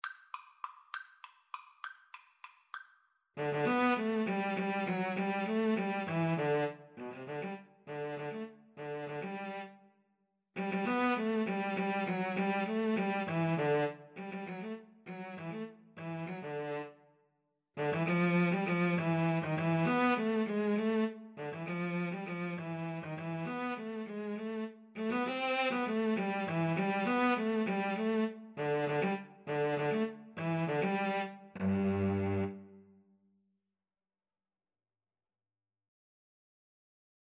3/8 (View more 3/8 Music)
Cello Duet  (View more Easy Cello Duet Music)
Classical (View more Classical Cello Duet Music)